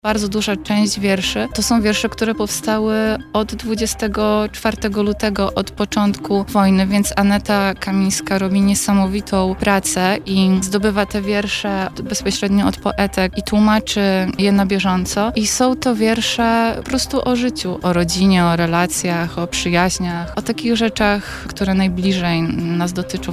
Więcej na ten temat mogliśmy usłyszeć podczas Porannej Rozmowy Radia Centrum.